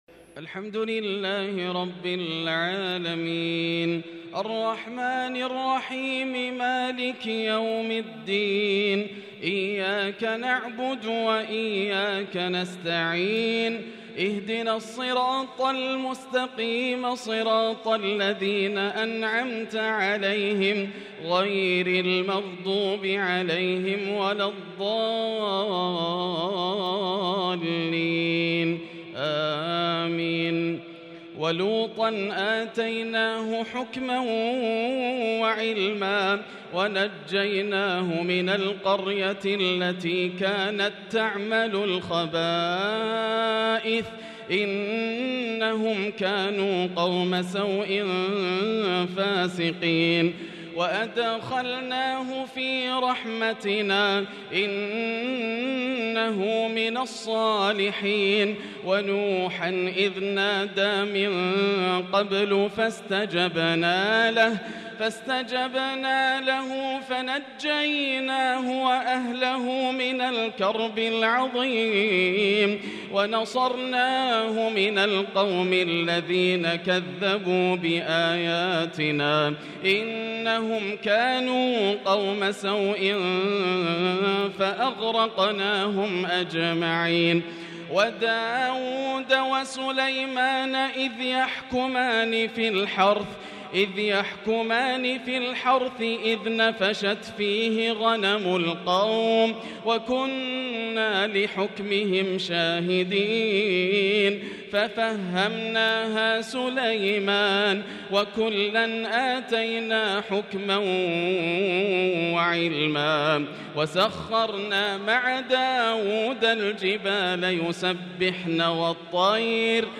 تراويح ليلة 30 رمضان 1443هـ من سورة الأنبياء (74-112) | taraweeh 30st night Ramadan 1443H Surah Al-Anbiya > تراويح الحرم المكي عام 1443 🕋 > التراويح - تلاوات الحرمين